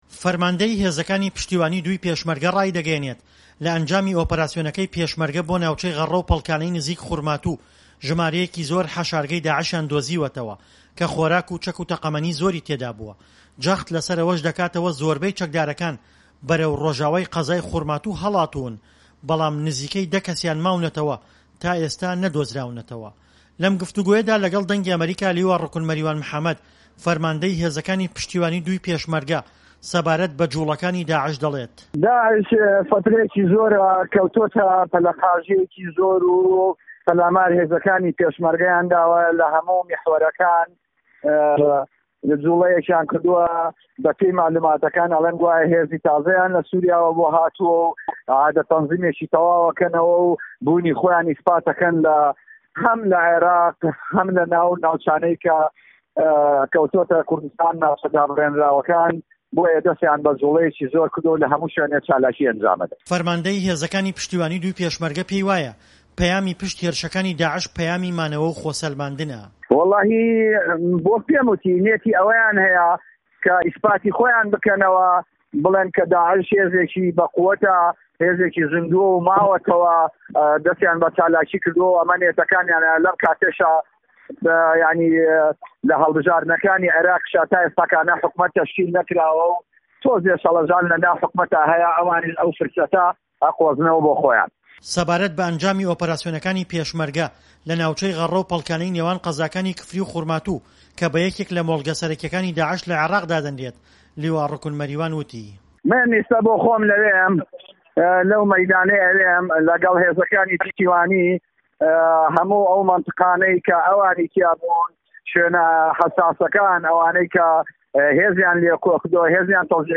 له‌م گفتووگۆیه‌دا له‌گه‌ڵ ده‌نگی ئه‌مه‌ریکا، لیوا ڕوکن مه‌ریوان محه‌مه‌د فه‌رمانده‌ی هێزه‌کانی پشتیوانی دووی پێشمه‌رگه‌ ده‌ڵێت"داعش له‌ سوریاوه‌ هێزی بۆ هاتووه‌، له‌ناو عێراقیش خۆی ڕێکخستووه‌ته‌وه‌، بۆیه‌ ده‌ستی به‌جوڵه‌یه‌کی زۆر کردووه‌ و له‌هه‌موو میحوه‌ره‌کانه‌وه‌ په‌لاماری پێشمه‌رگه‌یداوه."‌